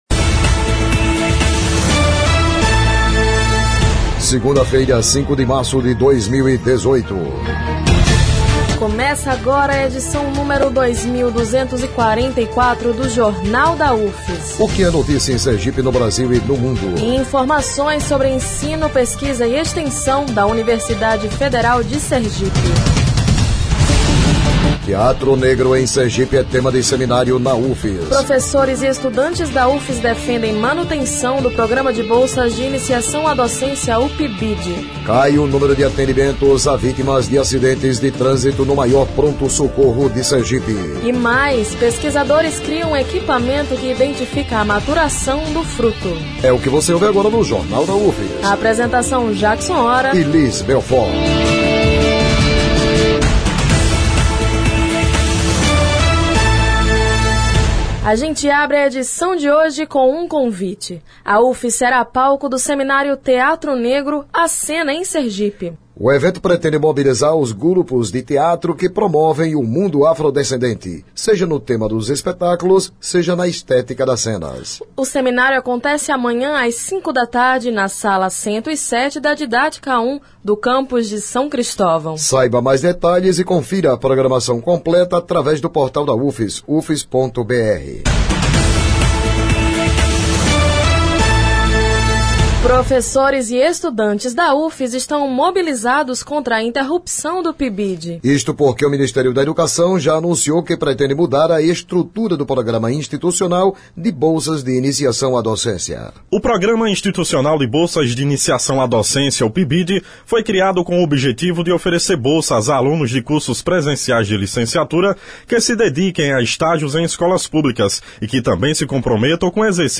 O Jornal da UFS desta segunda-feira, 05, destaca a moblização de professores e alunos da UFS contra a interrupção do PIBID. Isto porque o Ministério da Educação anunciou que pretende mudar a estrutura do Programa Instititucional de Bolsas de Iniciação à Docência. O noticiário vai ao ar às 11h na Rádio UFS FM, com reprises às 17h e 22h.